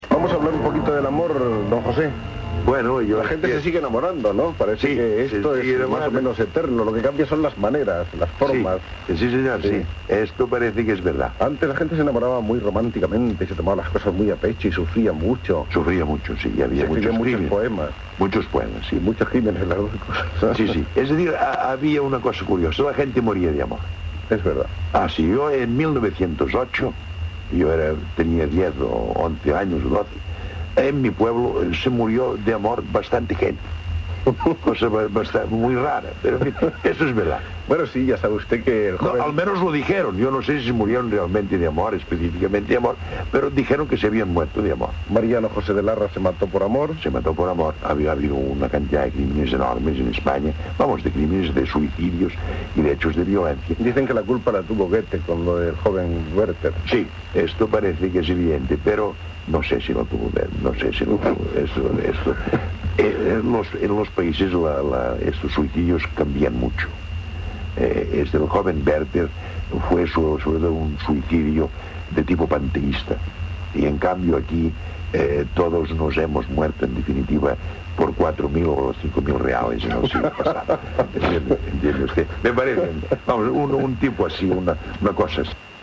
Entrevista a l'escriptor Josep Pla.
Fragment extret del programa "La ràdio que vam sentir", de Ràdio Barcelona, emès l'any 1999.